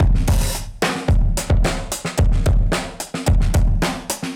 Index of /musicradar/dusty-funk-samples/Beats/110bpm/Alt Sound
DF_BeatB[dustier]_110-01.wav